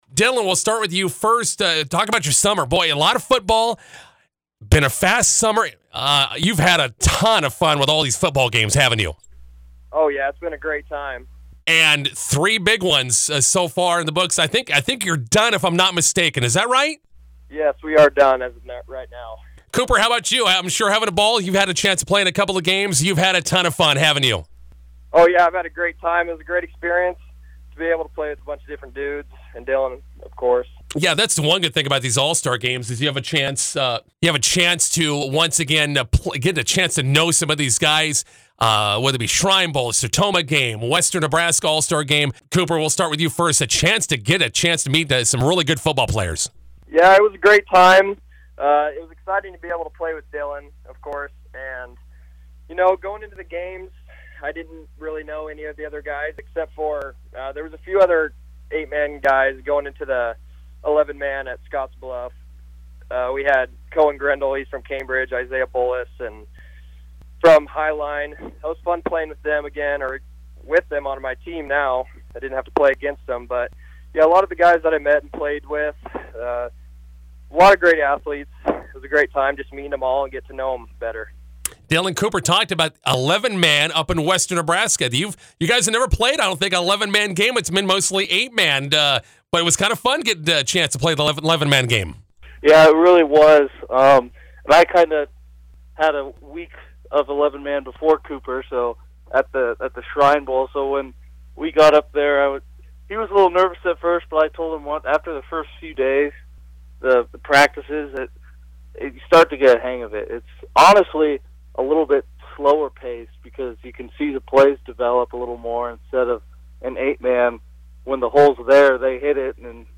INTERVIEW: Arapahoe HS football duo making the rounds on summer all-star games.